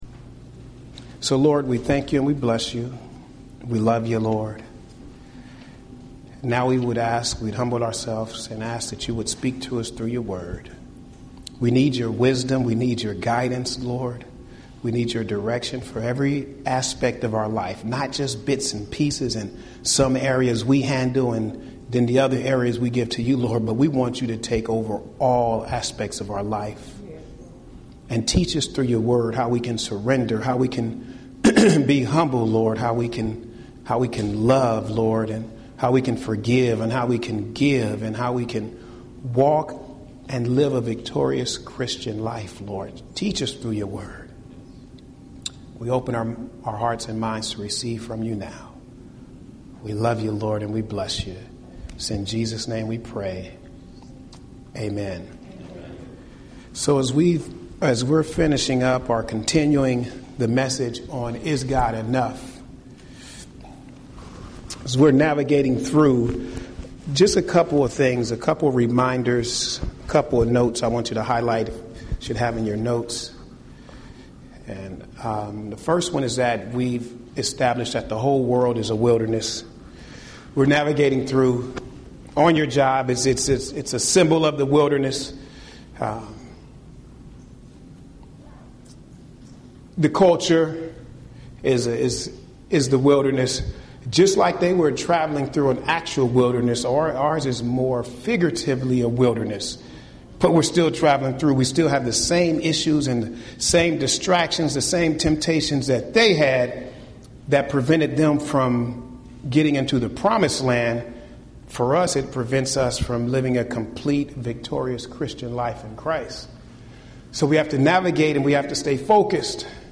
Home › Sermons › Is God Enough?